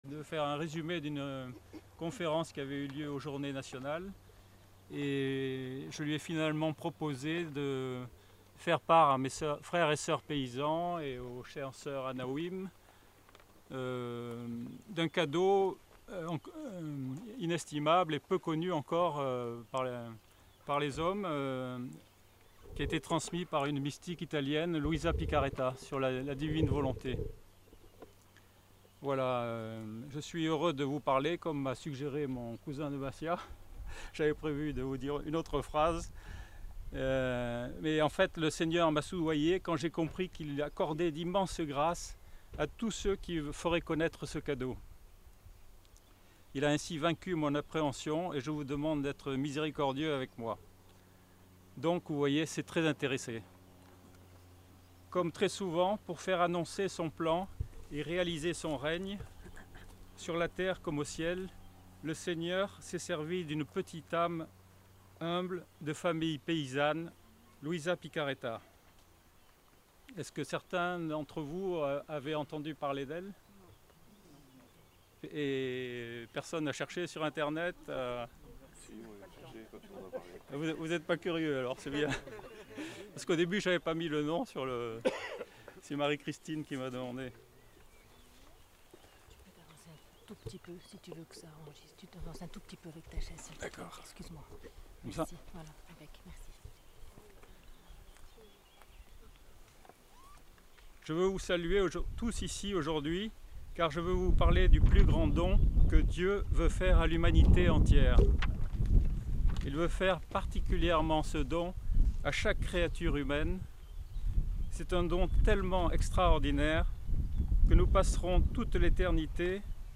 aux Journées Paysannes de 17 Juin 2018 au couvent des Anawim